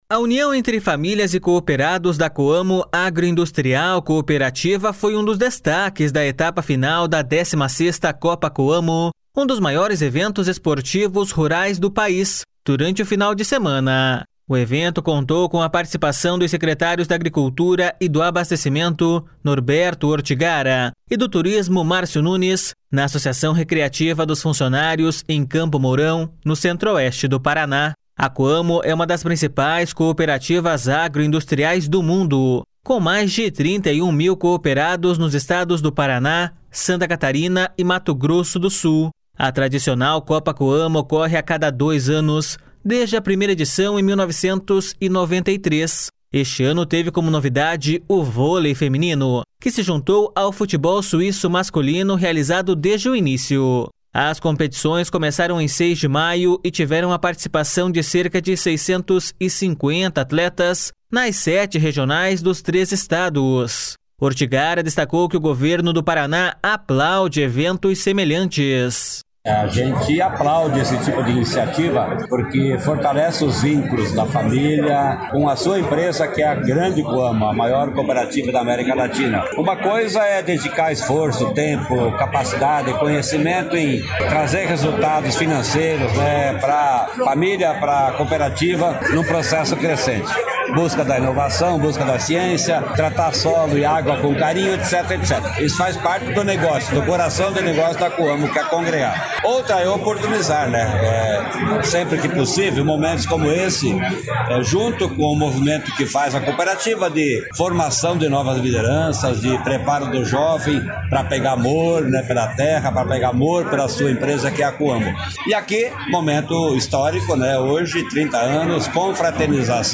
Ortigara destacou que o Governo do Paraná aplaude eventos semelhantes.// SONORA NORBERTO ORTIGARA.//